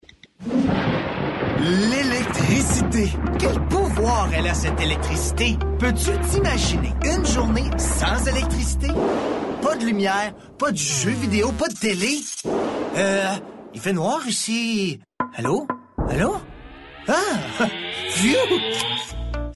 Narration 2 - FR